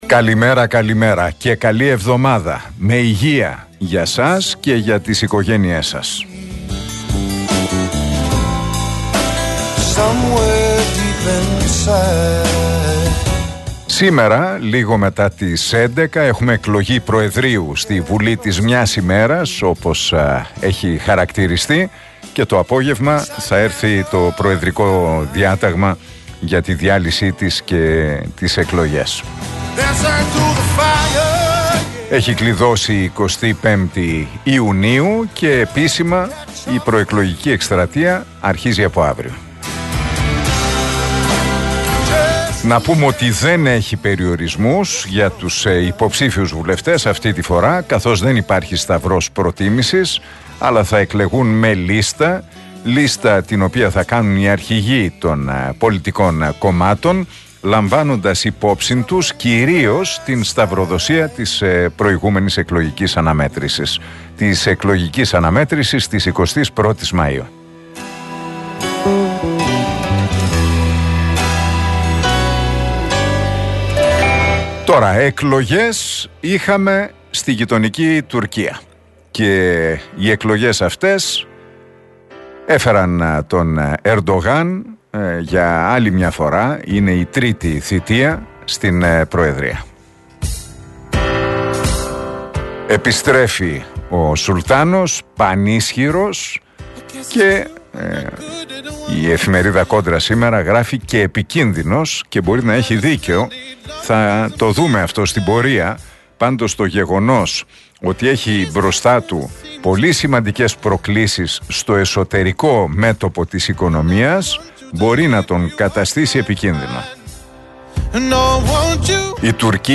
Ακούστε το σχόλιο του Νίκου Χατζηνικολάου στον RealFm 97,8, την Δευτέρα 29 Μαΐου 2023.